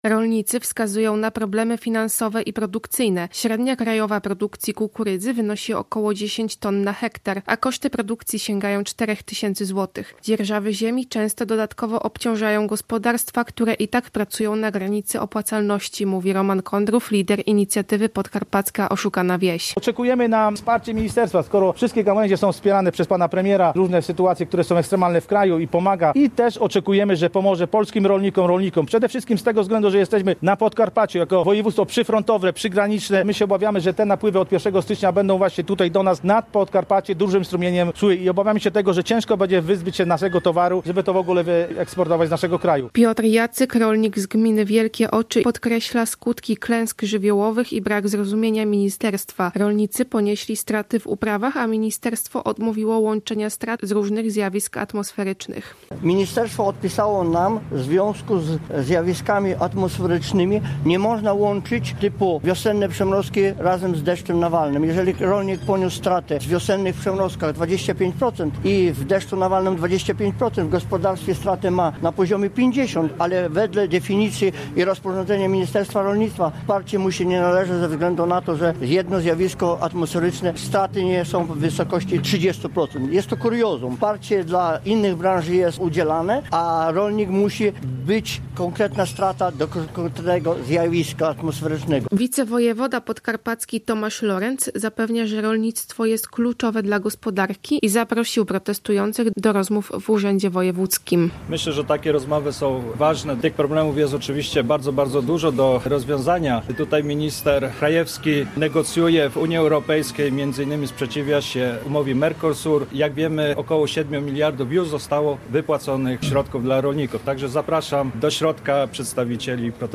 Protest rolników przed Podkarpackim Urzędem Wojewódzkim [ZDJĘCIA]